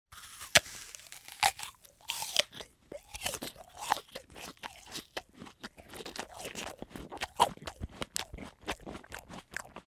Откусывают и едят яблоко
Человек ест яблоко с чавкающими звуками
chavkaet-jabloko.mp3